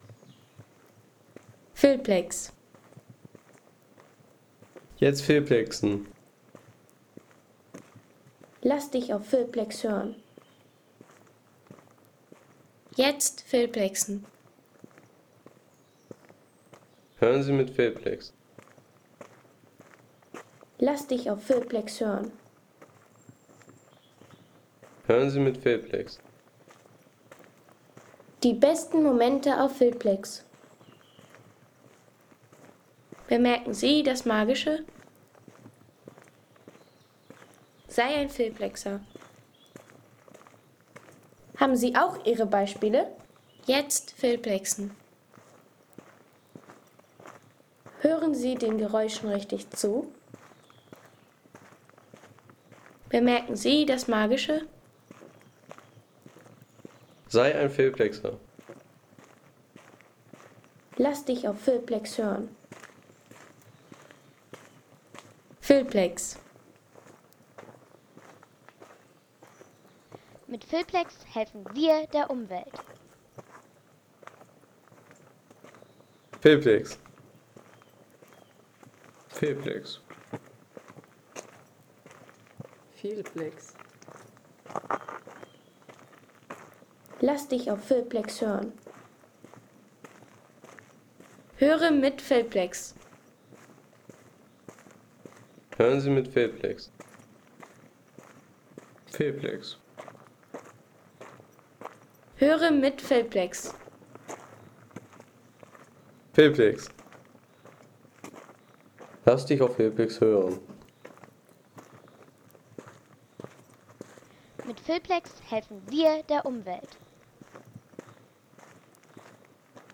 Wanderschritte im Wald
Die Wanderschritte im Wald – Elbsandsteingebirge, Sächsische Schwei ... 3,50 € Inkl. 19% MwSt.